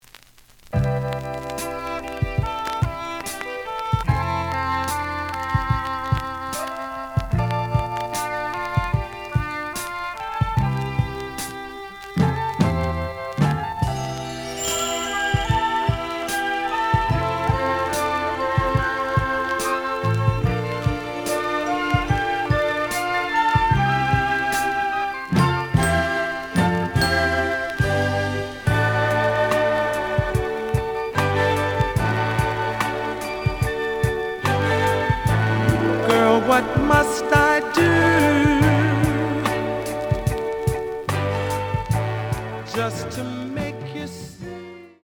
The audio sample is recorded from the actual item.
●Genre: Soul, 70's Soul
Slight edge warp.